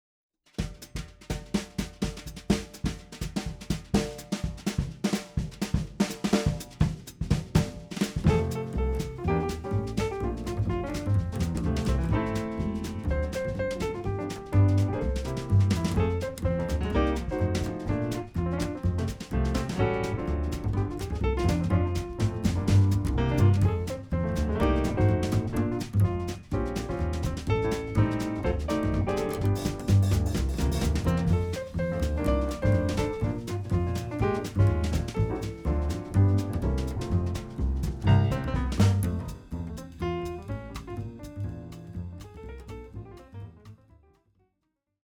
Hymn
bass
piano
drums